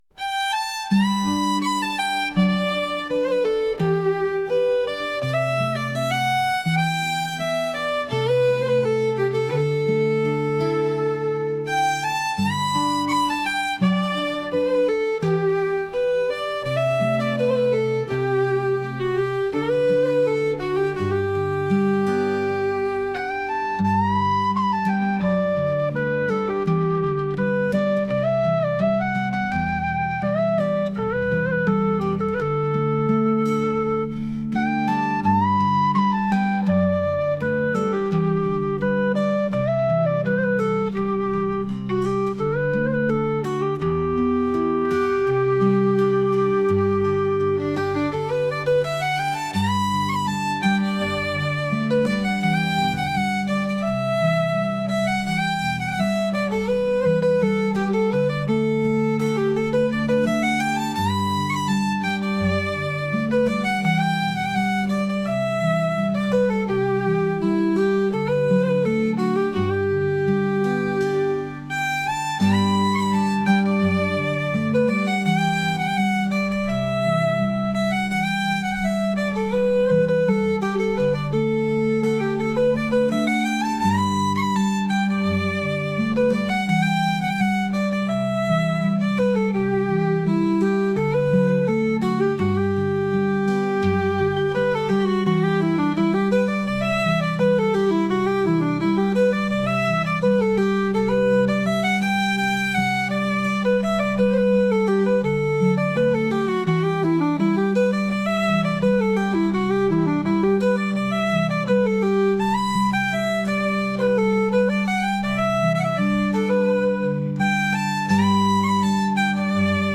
村で顔なじみの人と会うようなケルト音楽です。